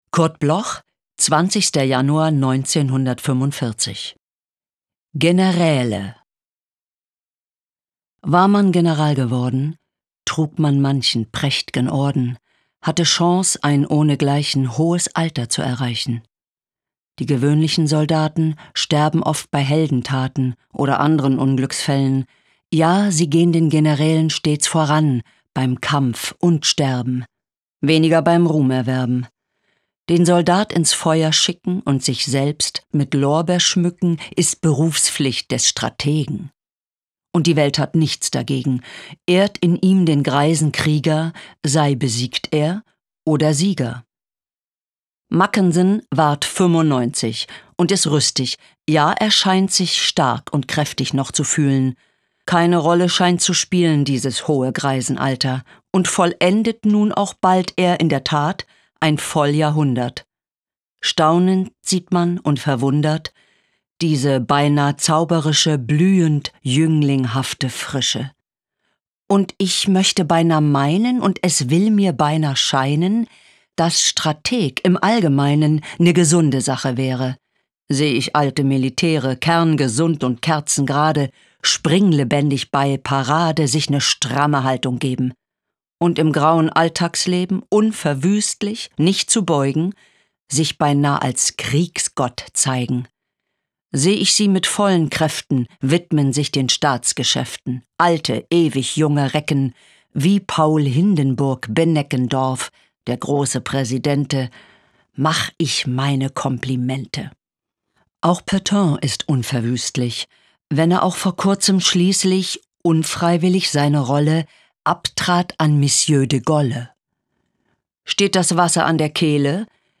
Rosa Enskat (* 1967) is a German actress.
Recording: speak low, Berlin · Editing: Kristen & Schmidt, Wiesbaden